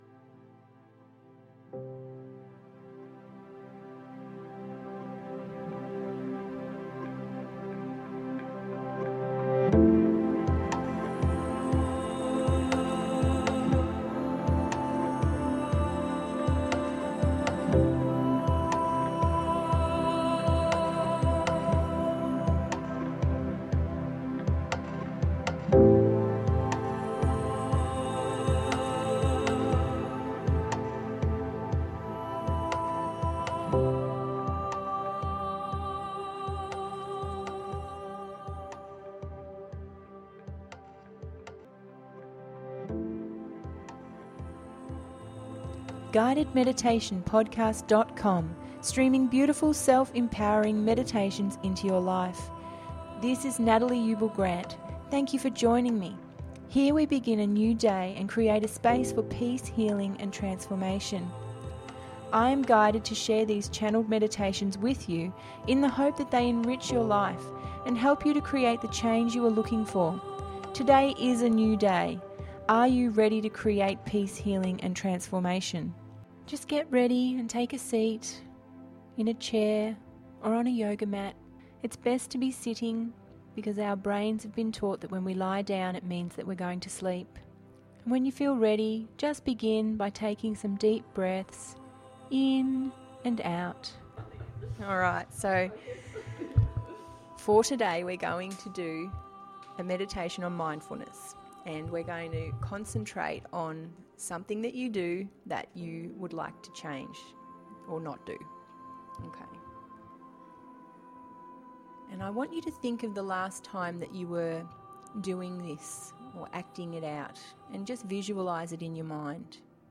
Today we have a mindfulness healing meditation that will help you let go of this old program. During the next 20 minutes you will have the opportunity to re-program a new way of being.